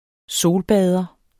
Udtale [ -ˌbæːðʌ ]